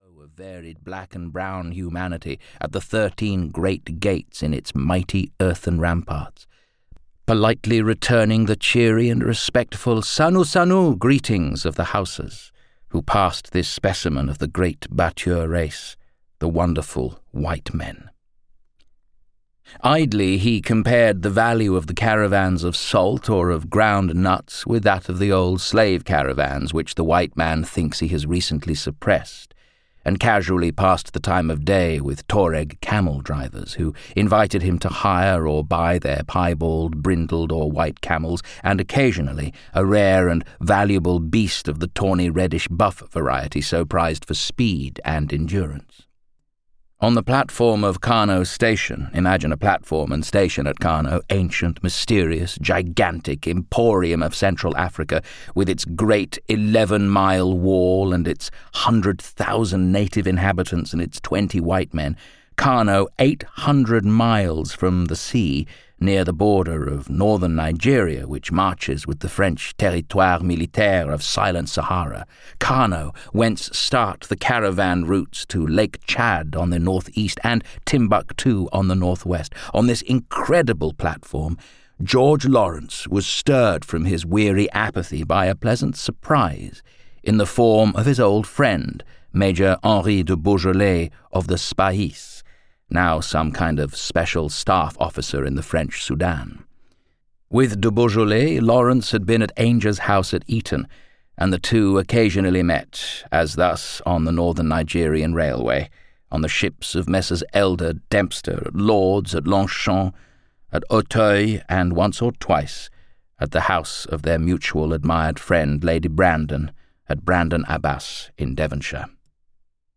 Beau Geste (EN) audiokniha
Ukázka z knihy